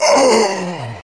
Play, download and share Quake Death 1 original sound button!!!!
quake-death-1.mp3